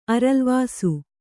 ♪ aralvāsu